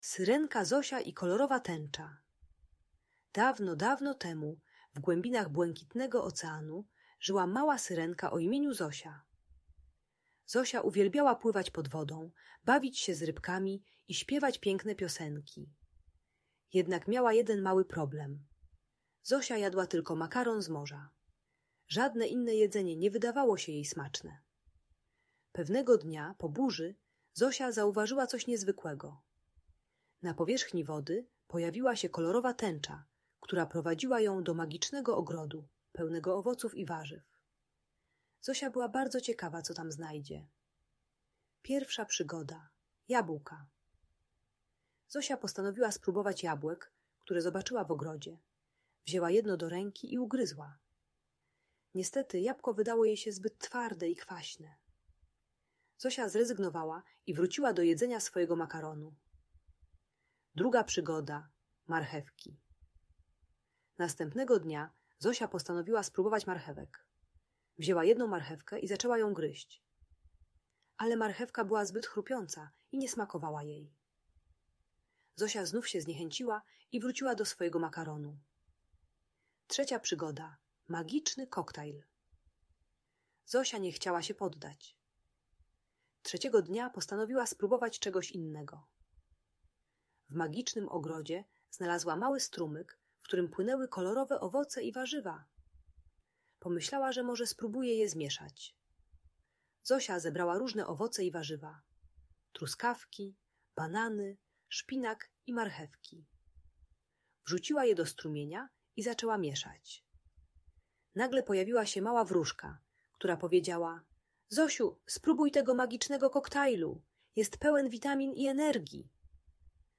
Historia o Małej Syrenki Zosi i Kolorowej Tęczy - Audiobajka dla dzieci